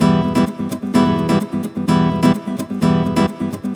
VEH2 Nylon Guitar Kit 128BPM